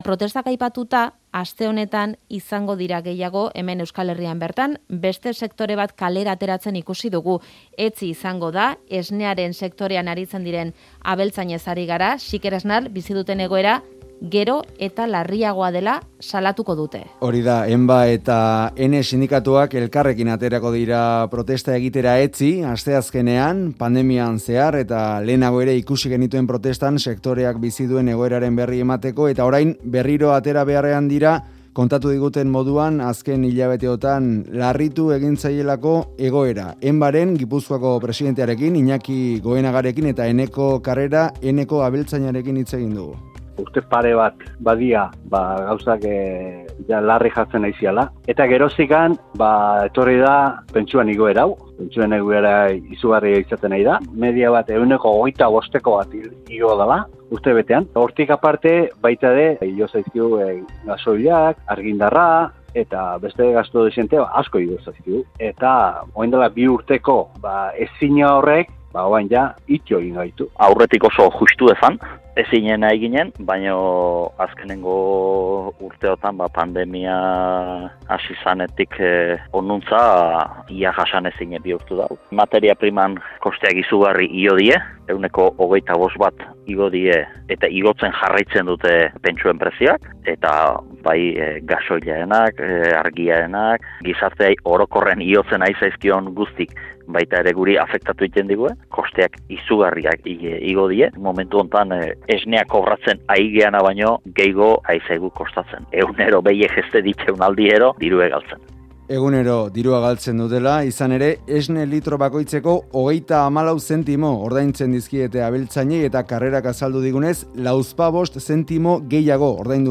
Audioa: ENBA eta EHNE sindikatuak elkarrekin mobilizatuko dira asteazkenean, esne sektorean aritzen diren abeltzaineko bizi duten "krisi larria" salatzeko. Bi sindikatuotako ordezkariekin hitz egin dugu, egoera gertutik ezagutzeko.